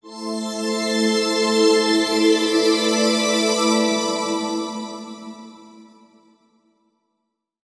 SYNTHPAD013_PROGR_125_A_SC3.wav
1 channel